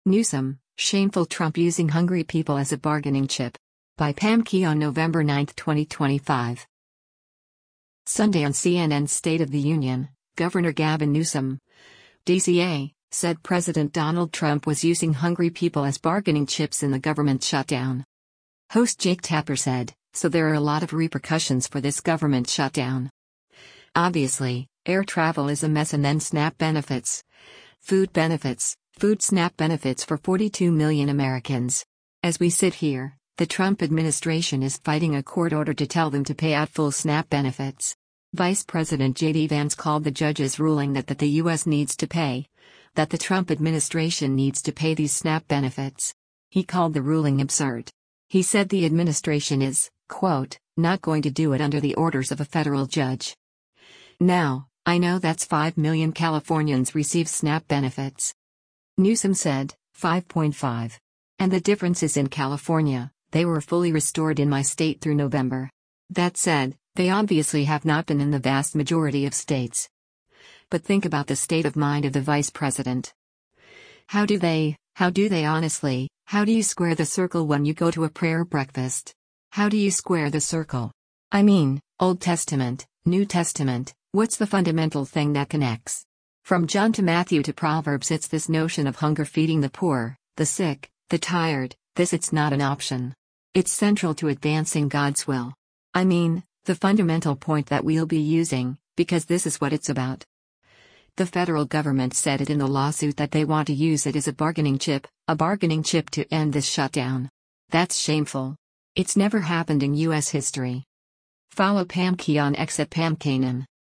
Sunday on CNN’s “State of the Union,” Gov. Gavin Newsom (D-CA) said President Donald Trump was using hungry people as bargaining chips in the government shutdown.